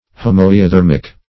\Ho*moi`o*therm"ic\, a. [Gr.